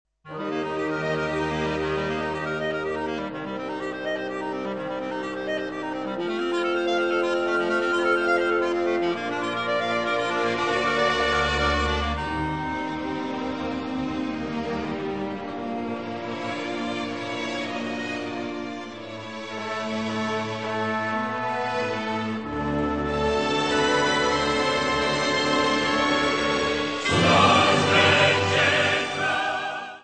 24 Polish Scout songs.